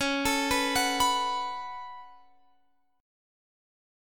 Db7sus4#5 Chord